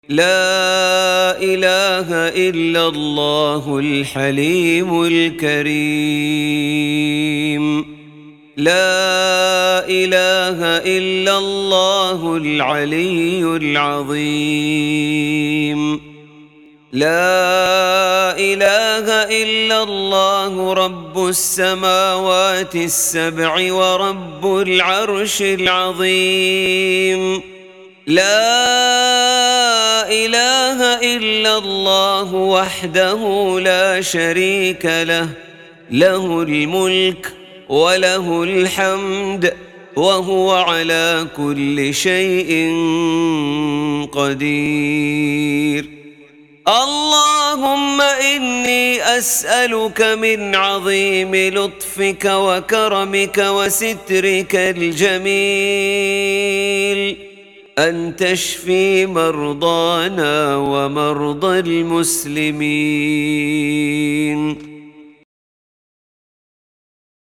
دعاء مؤثر يبدأ بتوحيد الله تعالى وتمجيد صفاته، ثم يختتم بالالتجاء إليه وسؤاله من لطفه وستره وكرمه أن يمن بالشفاء والعافية على المريض وجميع المسلمين. يعكس النص روح التفويض والثقة المطلقة في قدرة الله ورحمته.